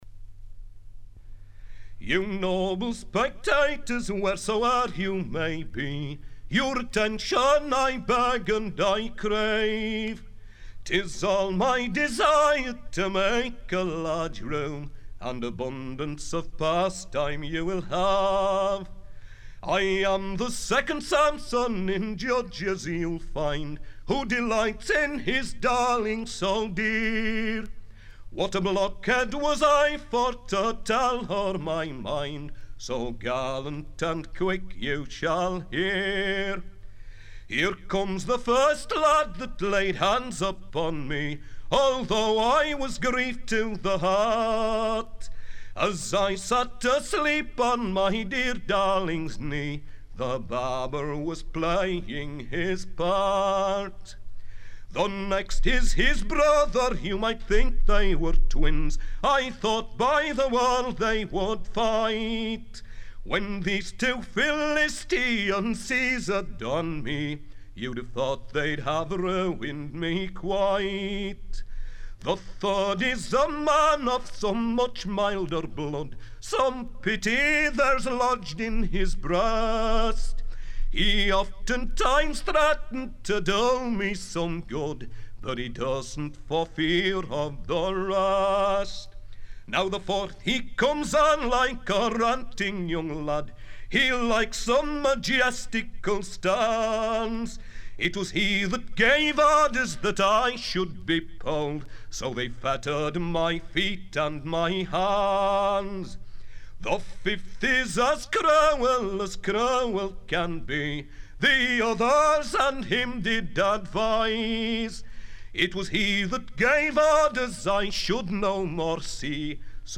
folk singer
his fine voice and lively interpretation of traditional English songs seemed to me to put him in the first rank of British singers.